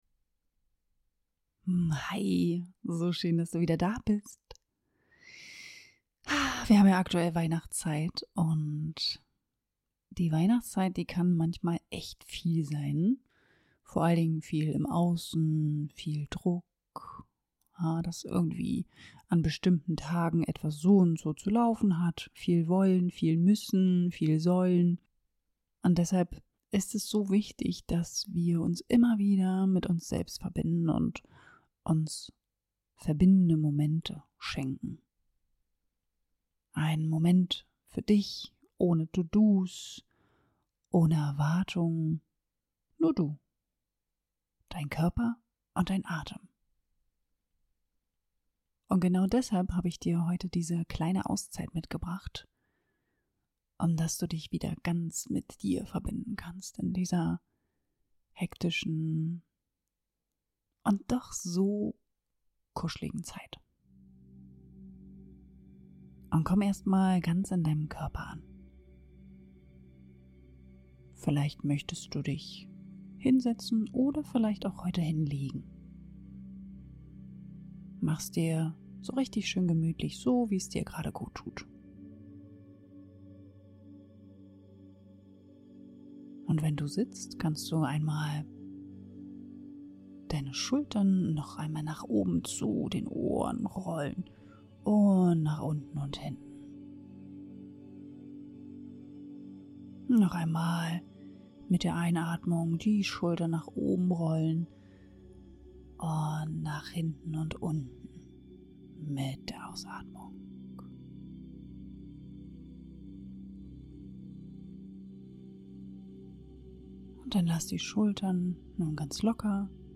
Weich werden im Weihnachtswirbel - eine achtsame Pause für dich (mit Musik) ~ Atemgesundheit - Balance statt Hektik Podcast
Diese kurze Meditation ist wie ein innerer Kamin: Eine Einladung, weich zu werden.